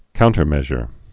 (kountər-mĕzhər)